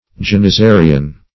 Search Result for " janizarian" : The Collaborative International Dictionary of English v.0.48: Janizarian \Jan`i*za"ri*an\, a. Of or pertaining to the janizaries, or their government.